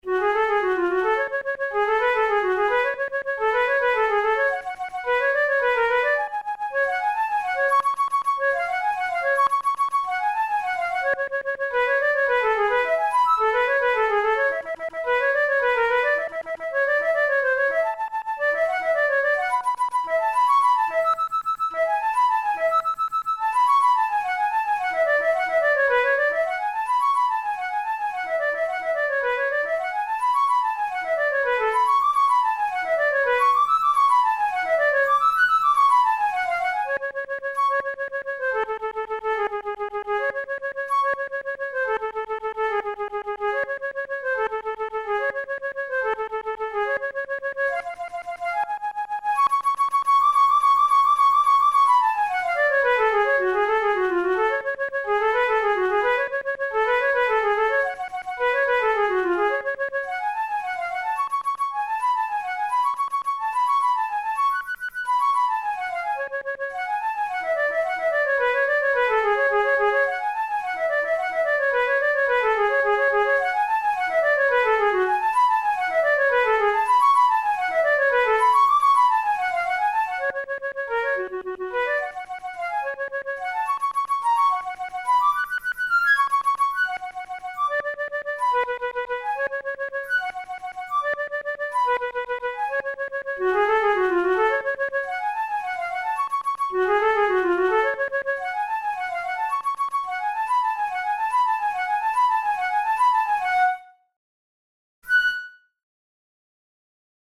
Etudes, Romantic, Written for Flute